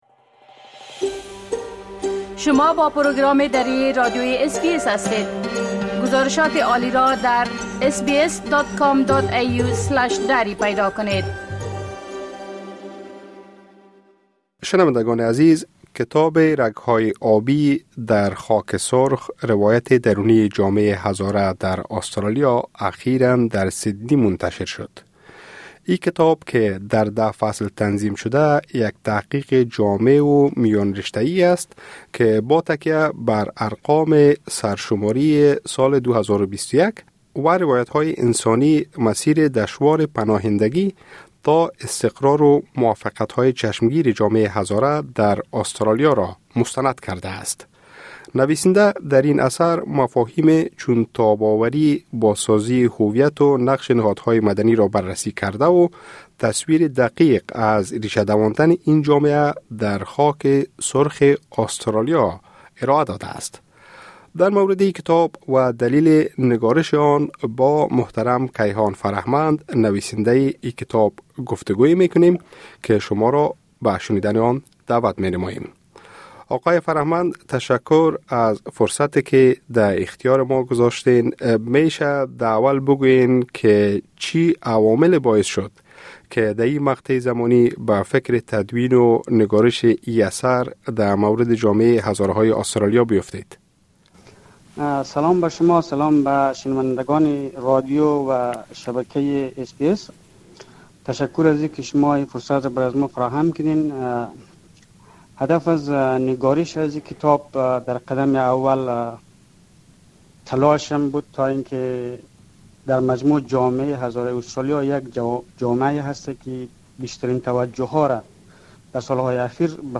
گفتگوی انجام دادیم